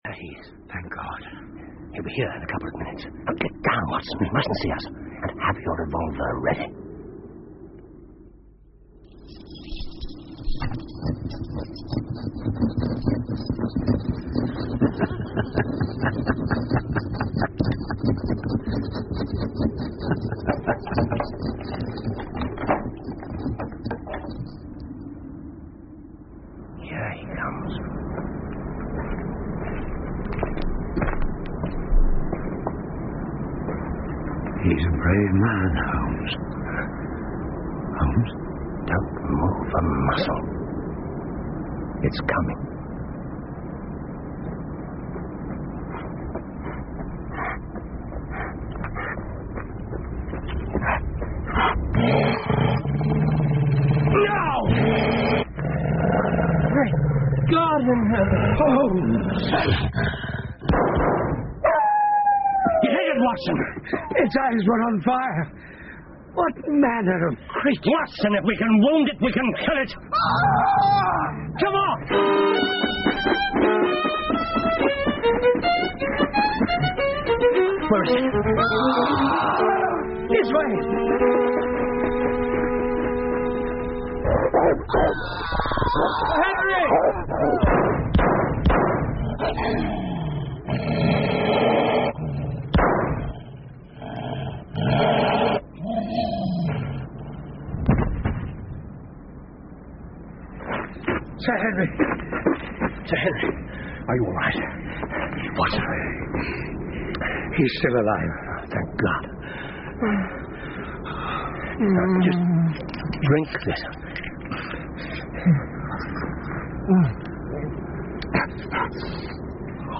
福尔摩斯广播剧 64 The Hound Of The Baskervilles - Part 02-10 听力文件下载—在线英语听力室